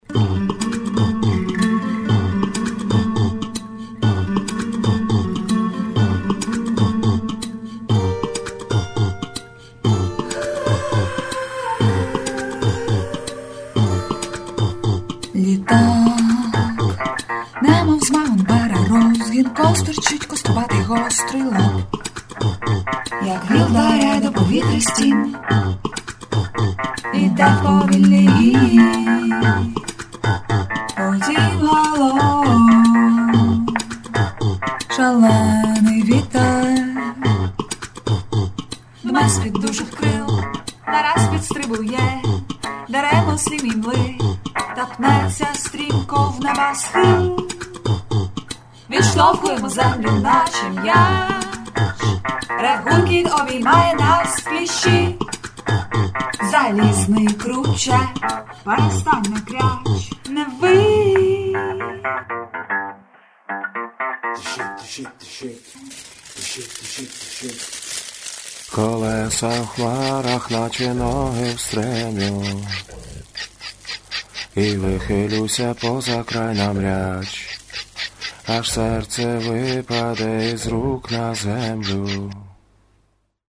dictophone recording